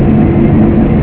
TANK.WAV